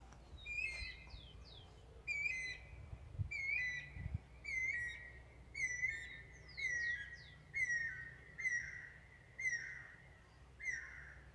Guira Cuckoo (Guira guira)
Province / Department: Entre Ríos
Detailed location: Colonia Ayuí, Paso del Águila
Condition: Wild
Certainty: Observed, Recorded vocal